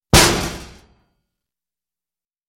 Звуки металла, ударов
Звук удара ногой по металлической поверхности